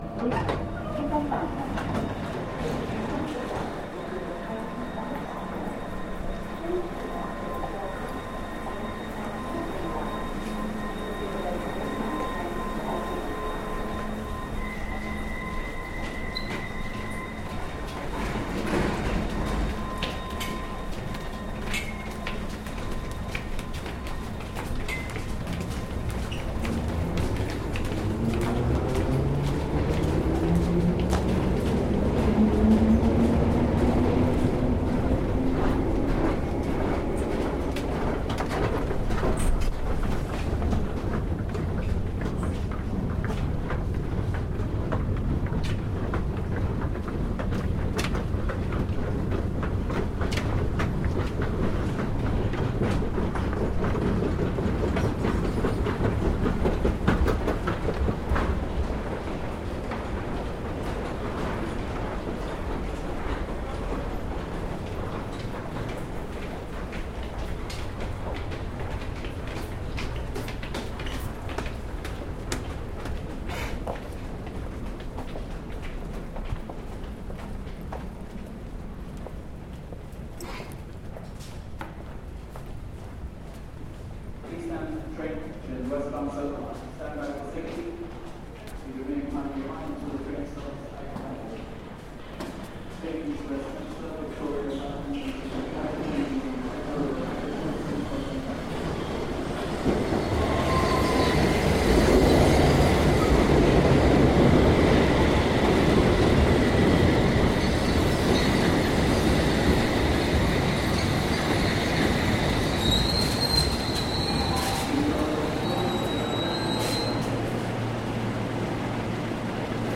Field recording from the London Underground by Cities and Memory.